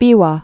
(bēwä)